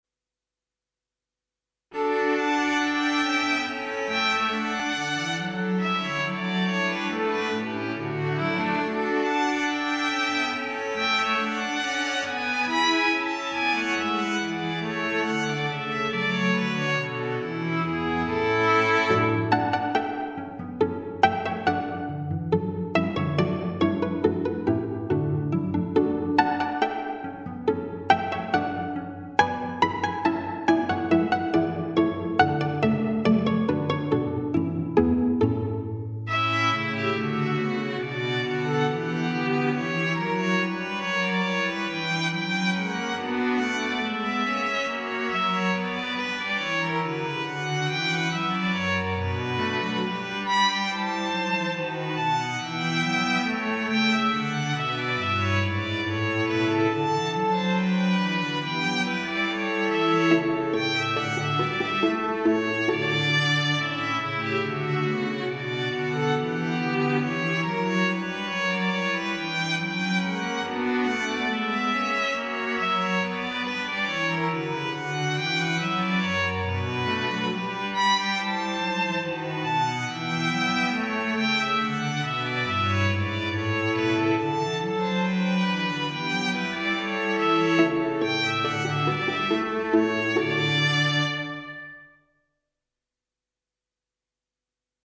Instrumentation:String Quartet
arranged for string quartet.